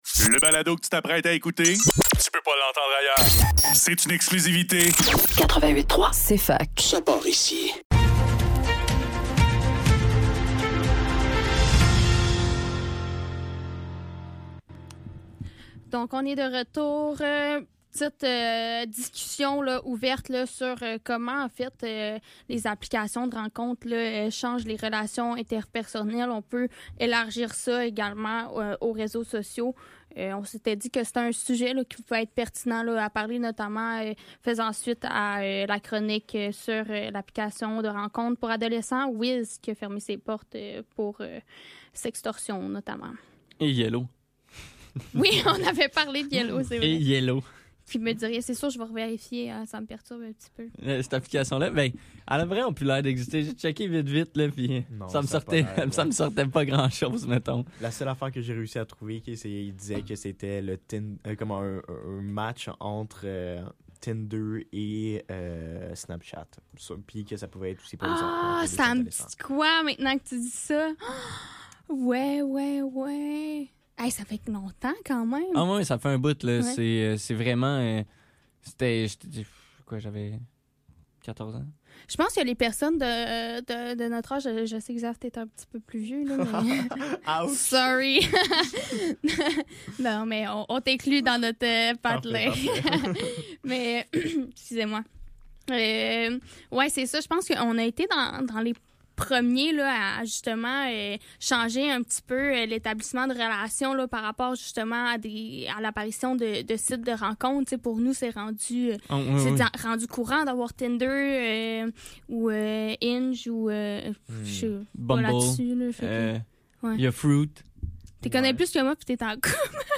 Le NEUF - Table ronde : comment les applications de rencontre changent les relations interpersonnelles - 15 février 2024